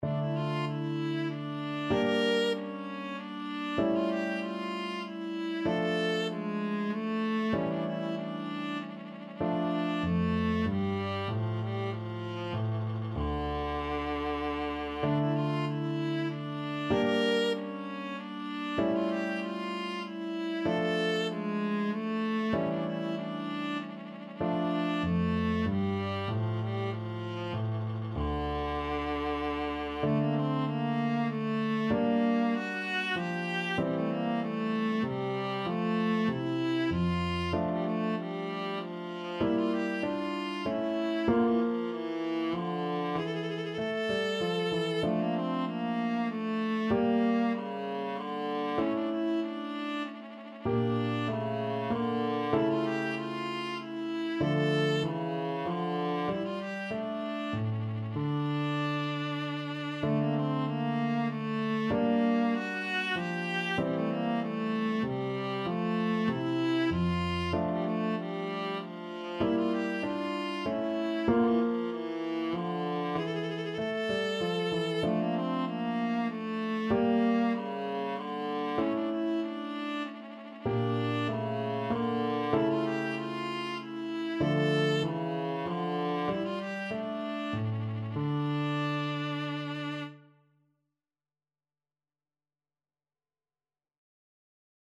3/8 (View more 3/8 Music)
Classical (View more Classical Viola Music)